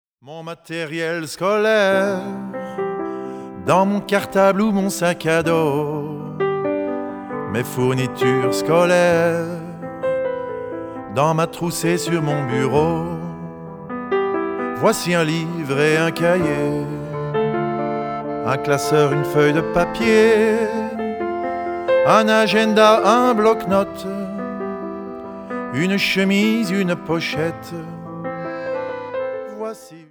Genre : World